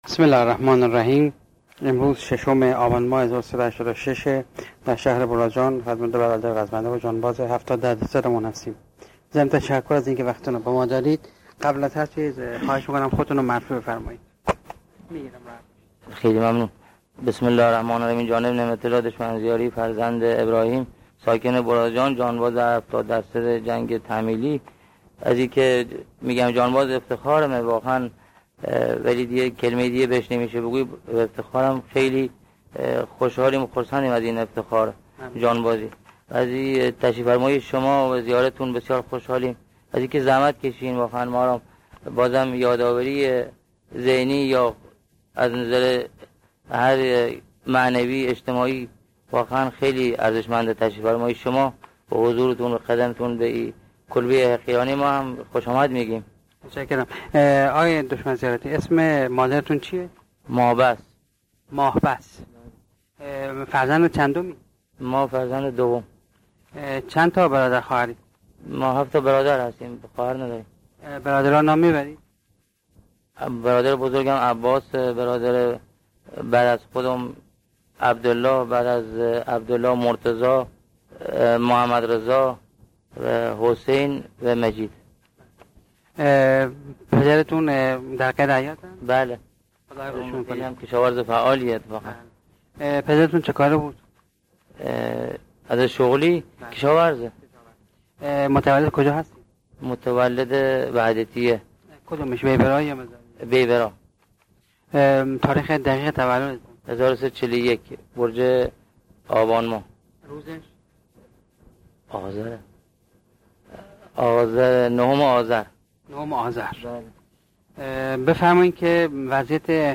صوت مصاحبه